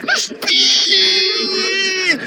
jeremy clarkson the speeeedd Meme Sound Effect